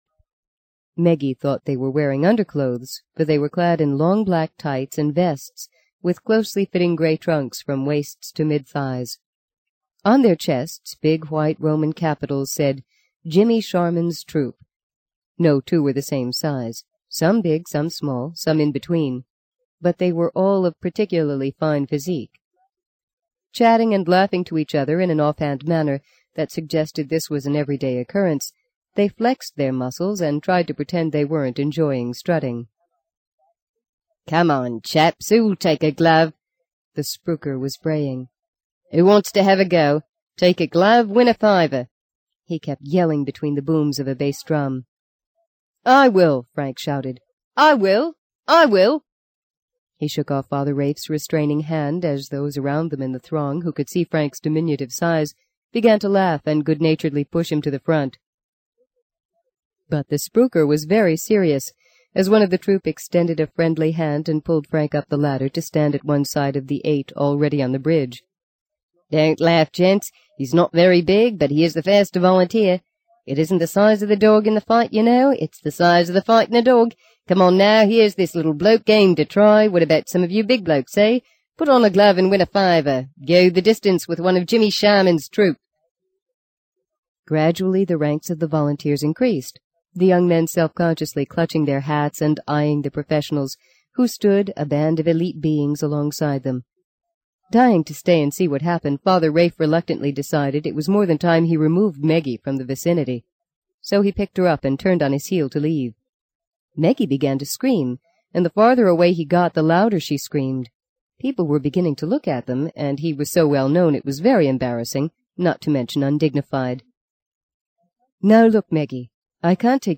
在线英语听力室【荆棘鸟】第五章 05的听力文件下载,荆棘鸟—双语有声读物—听力教程—英语听力—在线英语听力室